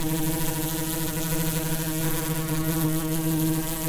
sounds_bumble_bee_01.ogg